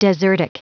Prononciation du mot desertic en anglais (fichier audio)
Prononciation du mot : desertic